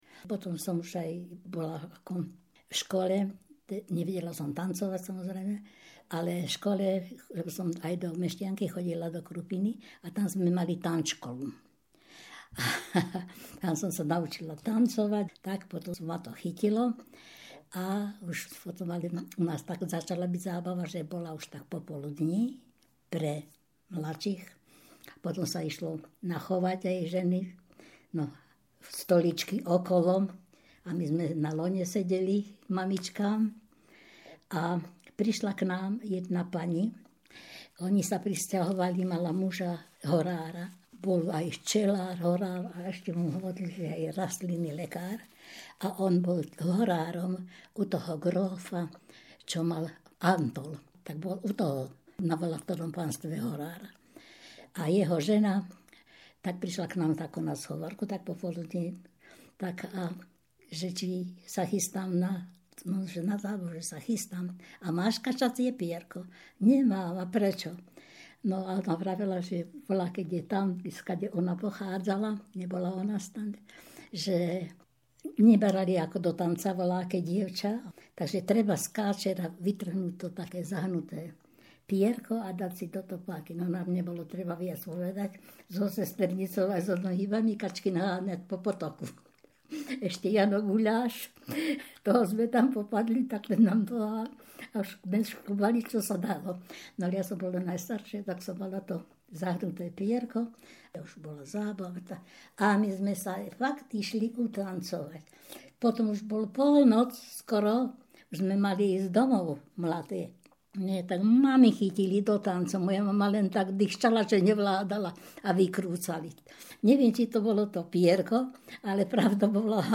spomienkové rozprávanie
Place of capture Hriňová